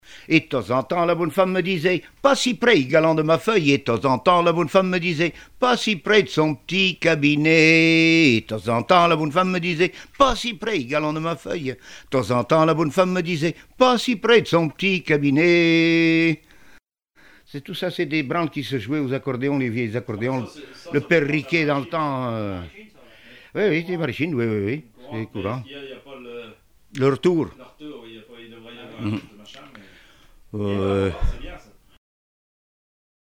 Divertissements d'adultes - Couplets à danser
branle : courante, maraîchine
Répertoire de chansons populaires et traditionnelles
Pièce musicale inédite